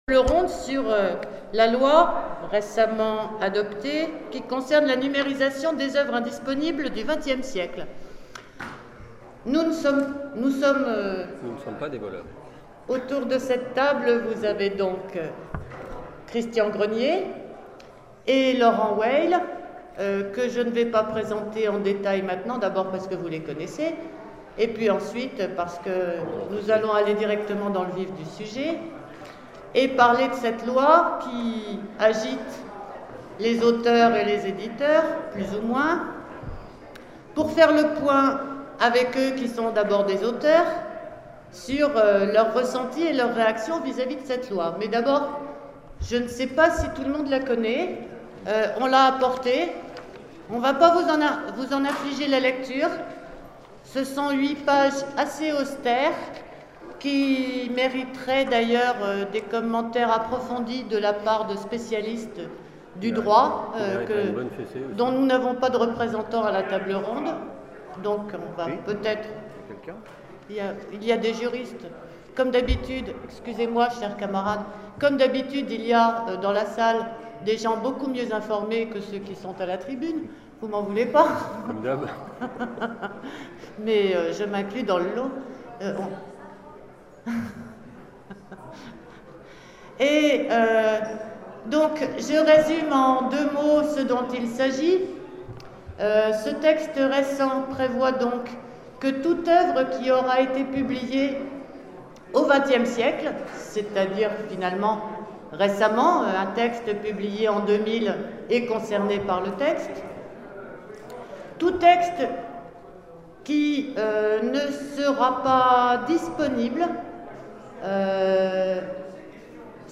Convention 2012 : Conférence sur la Loi sur les livres indisponibles du XXème siècle